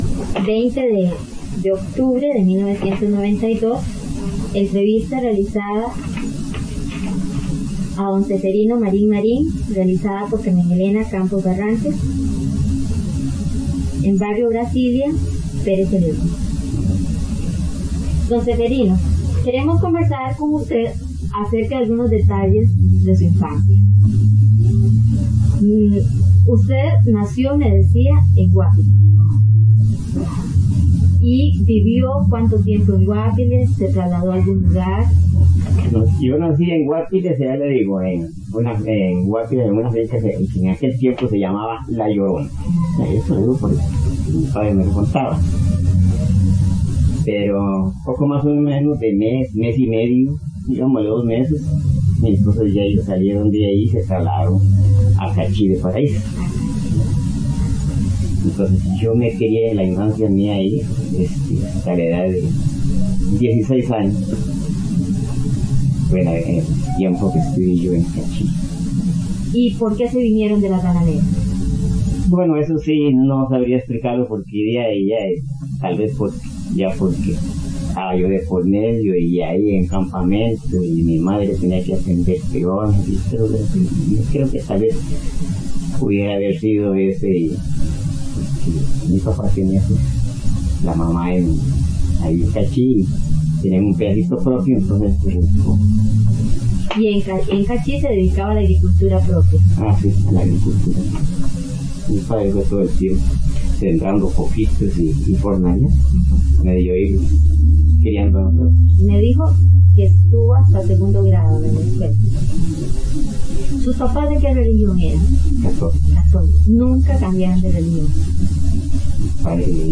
Notas: Casete de audio y digital